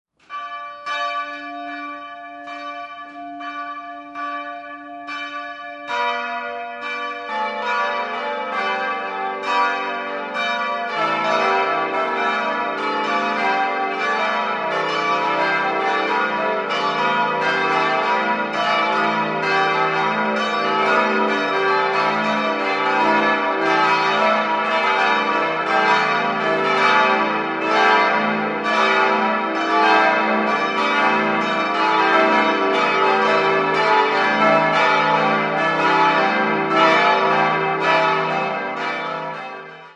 5-stimmiges ausgefülltes und erweitertes E-Moll-Geläute: e'-g'-a'-h'-d''